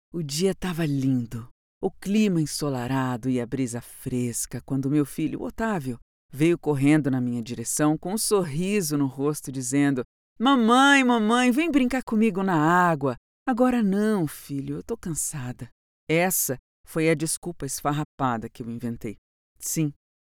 Sprechprobe: Sonstiges (Muttersprache):
Woman's voice, with peculiar and differentiated timbre. Voice that conveys credibility and confidence in a natural way. Neutral accent, which suits the client's needs and requirements.